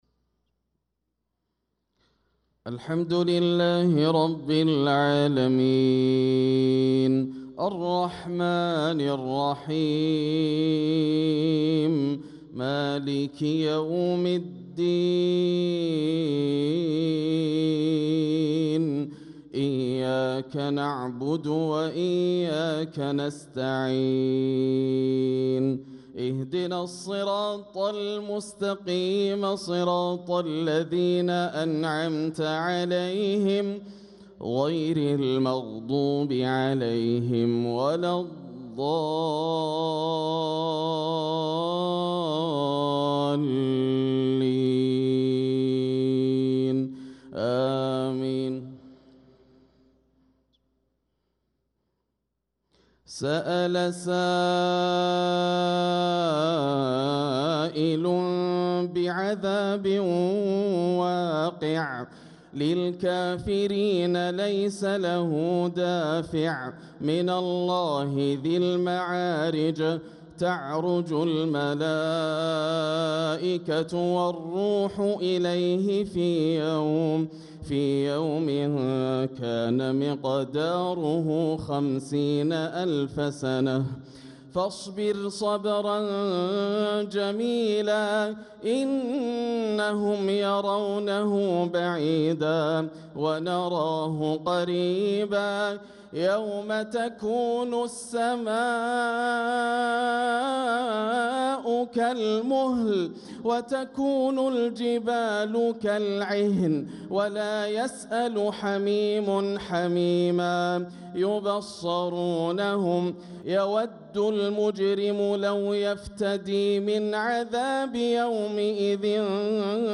صلاة العشاء للقارئ ياسر الدوسري 29 جمادي الأول 1446 هـ
تِلَاوَات الْحَرَمَيْن .